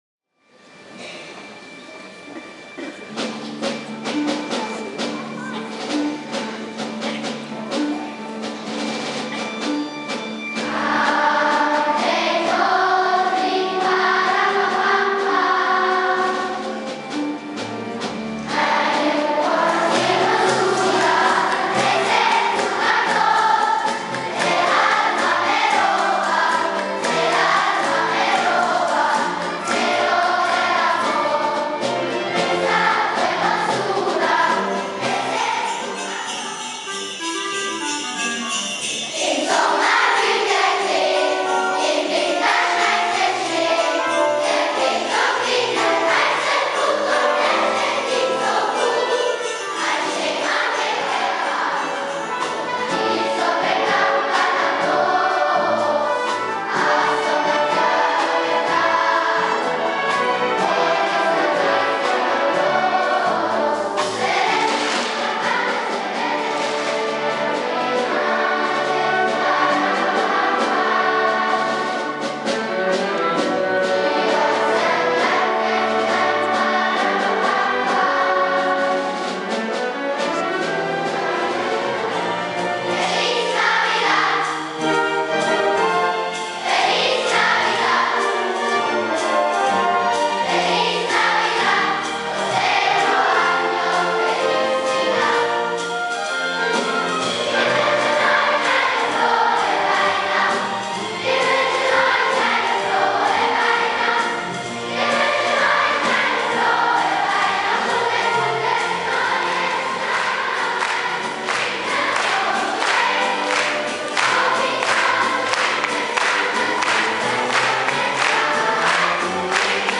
Insgesamt sechs Klassen aus den Schulanlagen Aebnit und Horbern und damit über 120 Schüler/-innen sowie 12 Lehrpersonen sangen am Konzert "da Pacem" im Casino Bern im Rahmen des Schulmusikprojekts "Sing mit uns". Damit war der diesjährige Chor zu einem grossen Teil mit Kindern aus der Gemeinde Muri besetzt.
Eine kleine Hörprobe (Live-Mitschnitt, nicht bearbeitet) können Sie sich